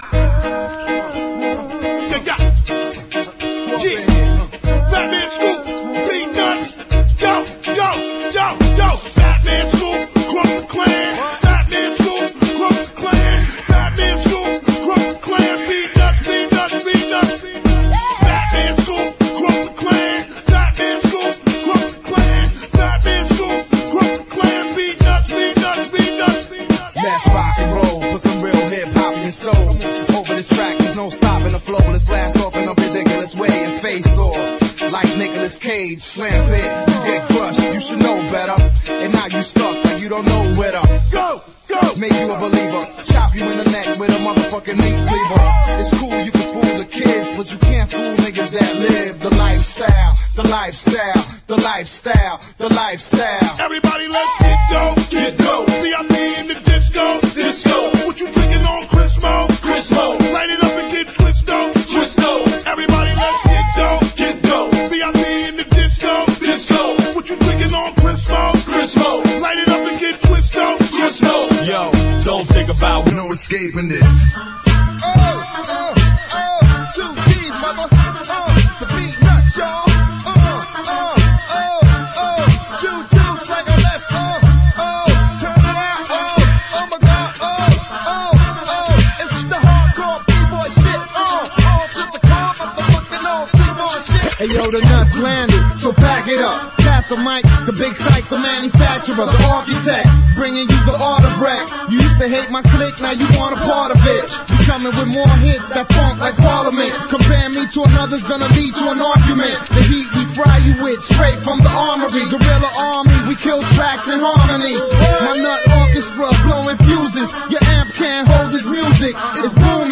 UK UNDERGROUND HIP HOP
メロウ且つドープなUK UNDERGROUND HIP HOP！